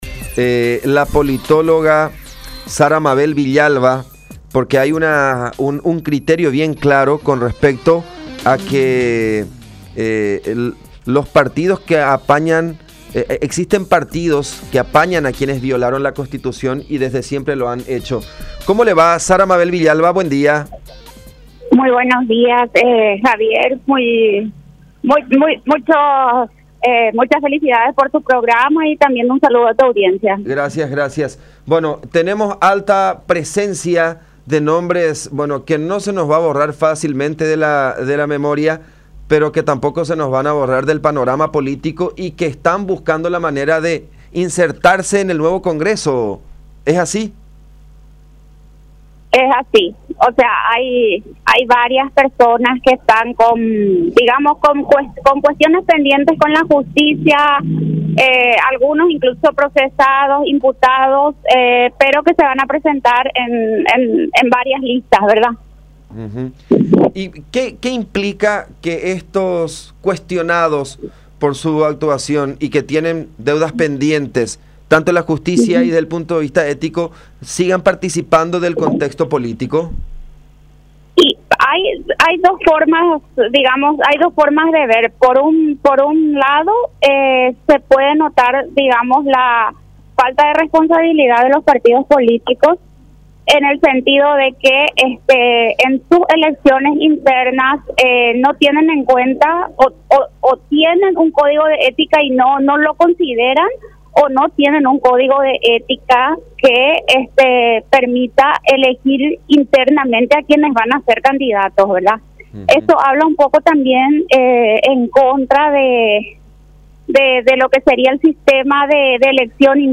Sobre el punto, dijo en contacto con La Unión R800 AM que, al parecer, no se tiene en cuenta a las voces de los afiliados, debido a que afirma que miles de personas cuestionan a los candidatos de sus mismos partidos, pero estos de igual manera aceptan que se postulen.